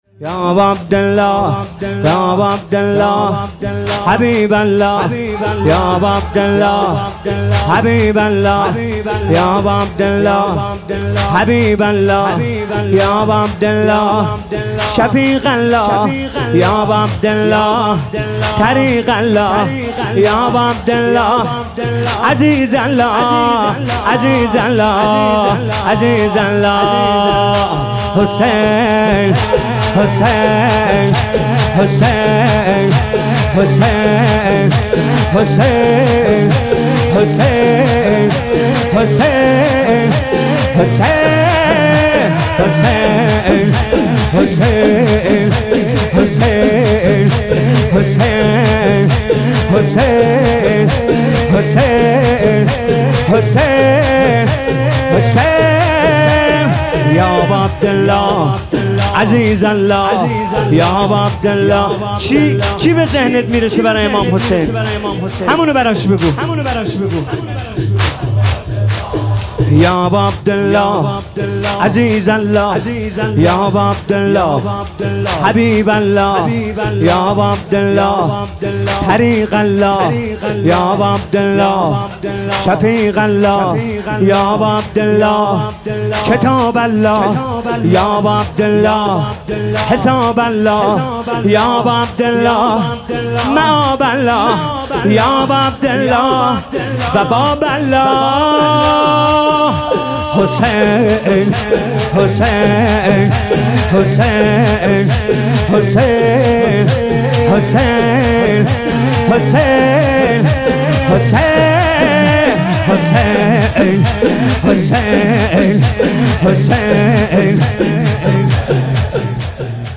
شور - یا اباعبدالله حبیب الله - كربلايی حسين طاهری
شور - هیئت هفتگی - كربلايی حسين طاهری